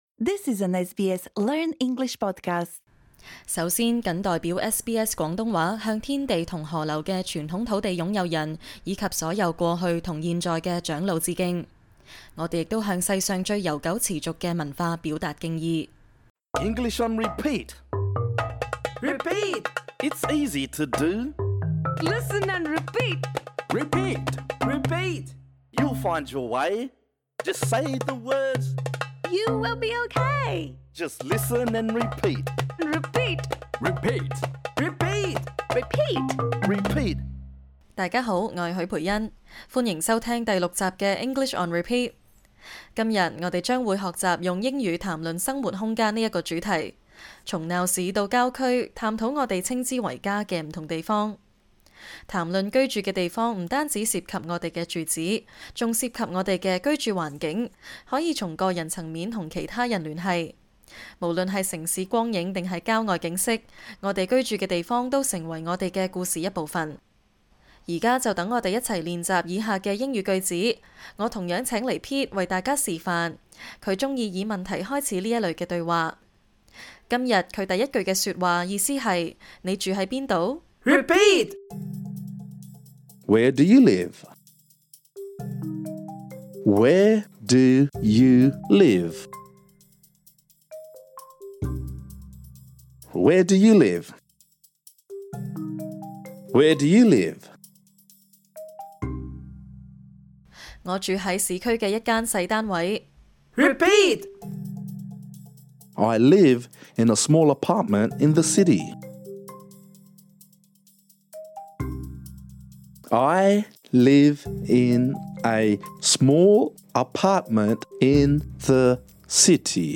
這個教學專為初學者而設。 本集內容將練習以下的英語短句： Where do you live? I live in a small apartment in the city. I live in a house with two bedrooms. We are renting. Are you close to public transport?